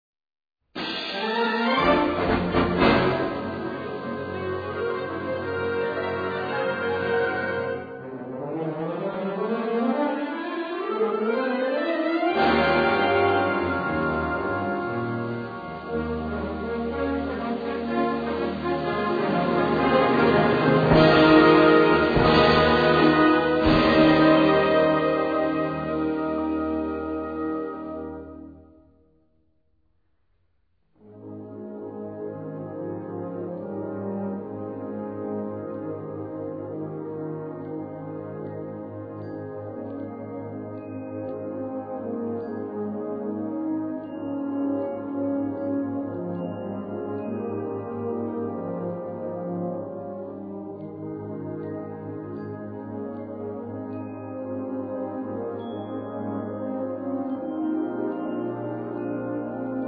Gattung: 4 Variationen
Besetzung: Blasorchester